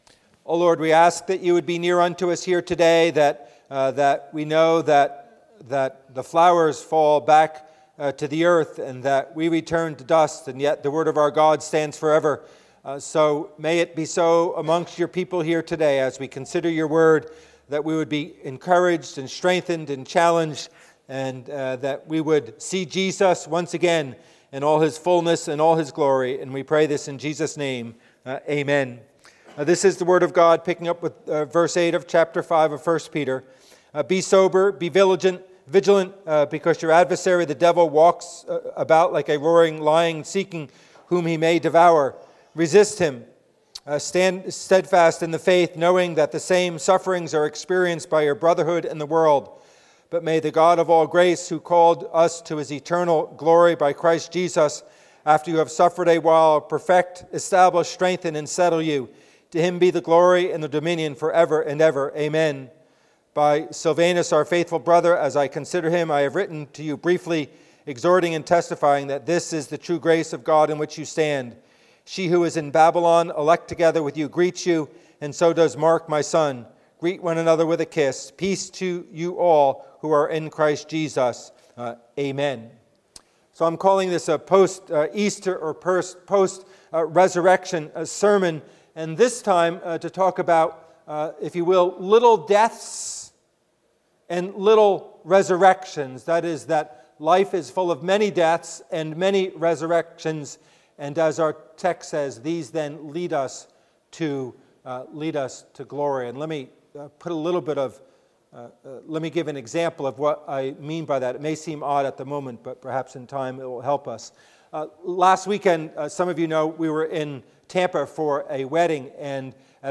1 Peter 5:8-14 Service Type: Worship Service « An Uplifting Visitation